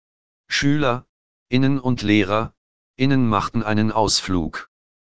Populär ist vor allem die freie Software NVDA (NonVisual Desktop Access), der auch für die folgenden Audiobeispiele genutzt wird.
Eine männliche Computerstimme (NVDA) liest: Schüler (pause) innen und Lehrer (pause) innen machten einen Ausflug. Das wirkt künstlich, aber kommt der Sprechpause, wie sie aus gegenderten Radiobeiträgen beispielsweise bekannt ist, am nähesten.